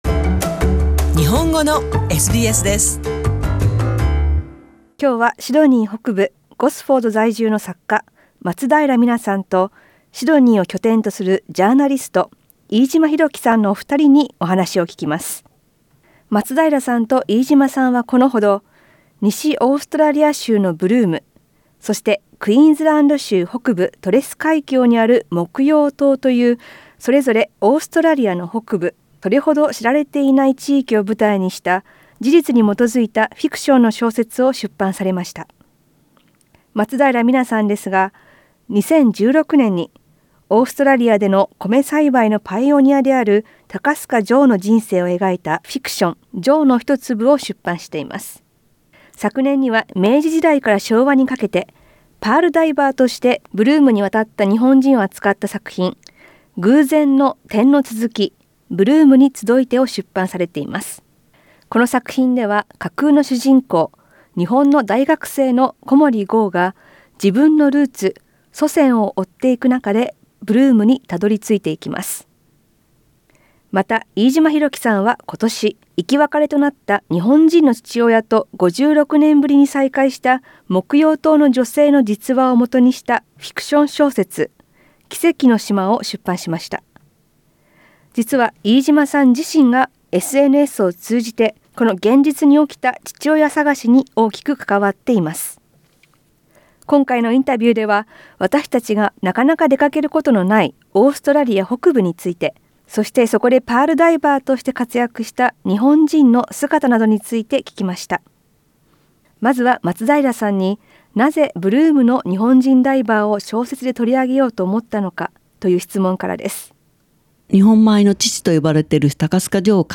日本人作家2人に聞く、ブルームと木曜島